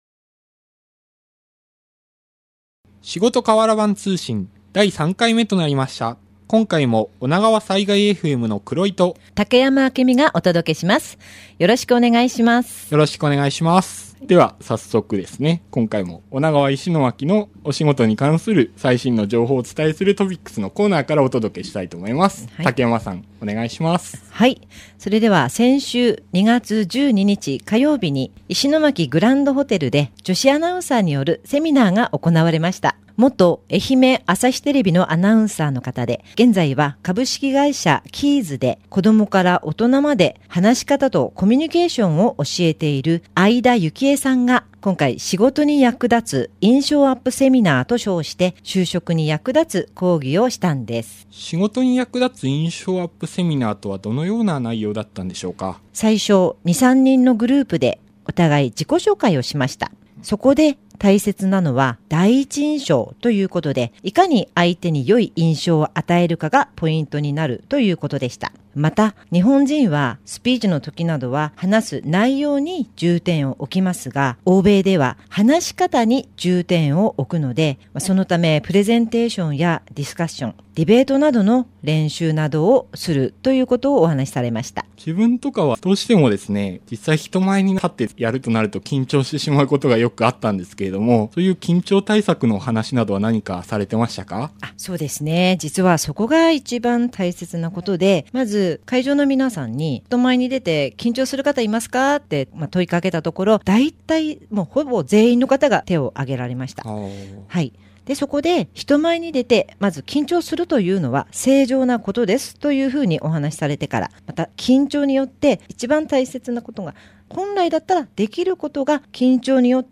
【企業インタビュー】